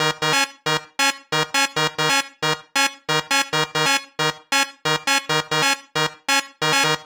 Lead 136-BPM D#.wav